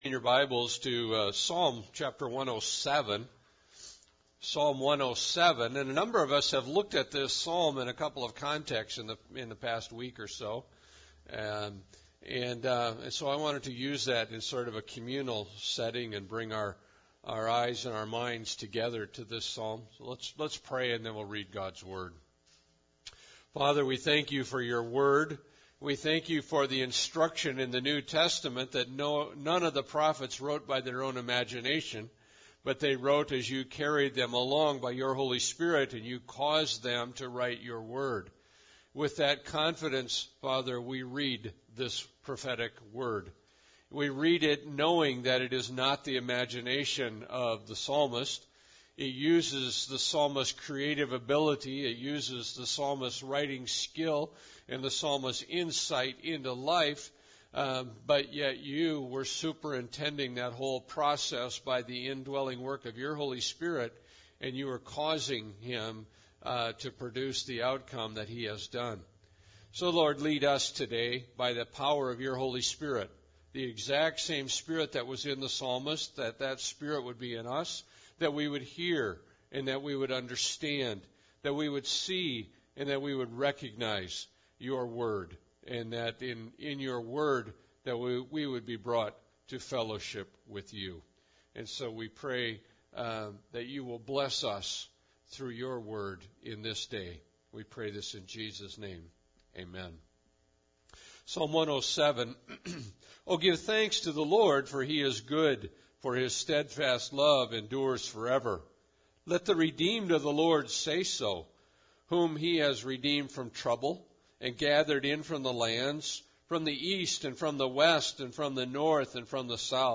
Thanksgiving Day Service – 2013